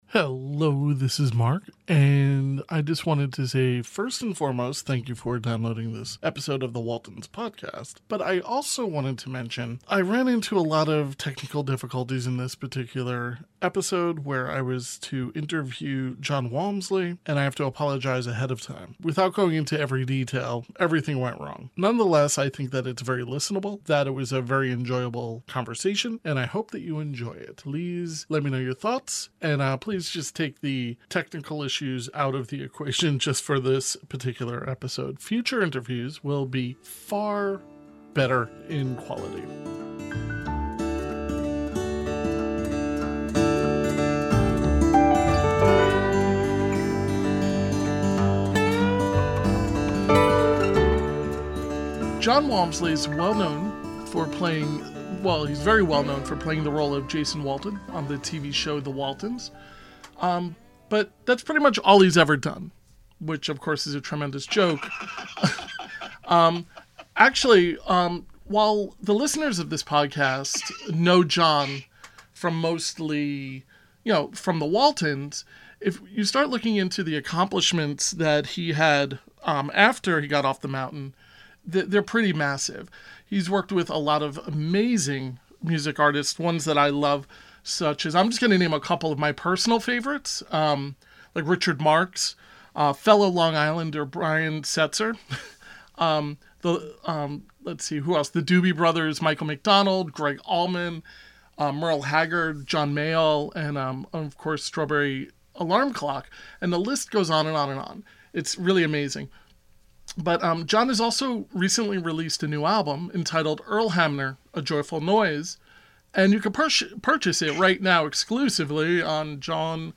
Unfortunately for me, I had a technical breakdown and needed to record this discussion in a very unconventional way so I apologize ahead of time for the audio issues! Jon was incredible and I had such a great time discussing everything from cars, guitars, his music and his time on The Waltons!